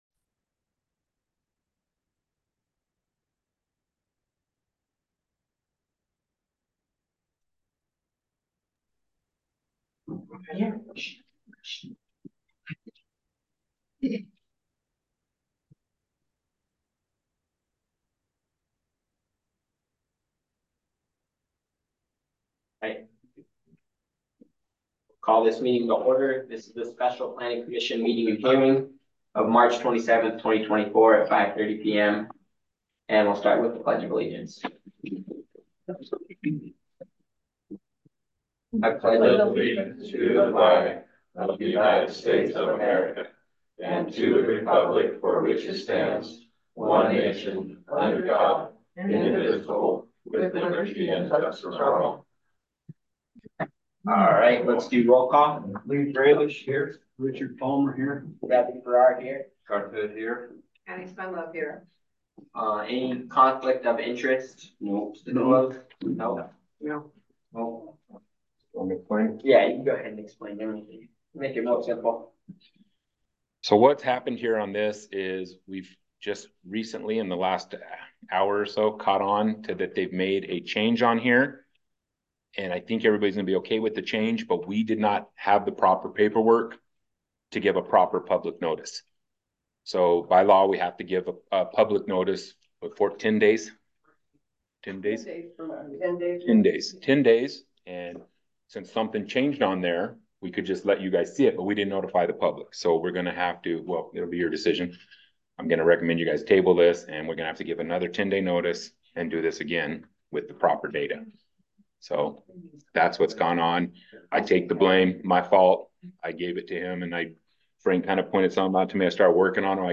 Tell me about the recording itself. In accordance with state statute, one or more council members may be connected via speakerphone.